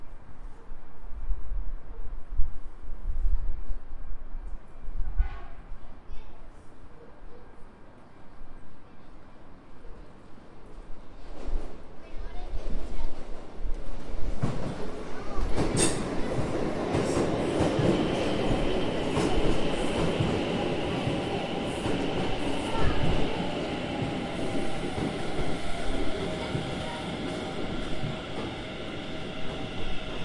描述：一个火车站的领域记录在布宜诺斯艾利斯，阿根廷。
Tag: 现场记录 街道 火车 城市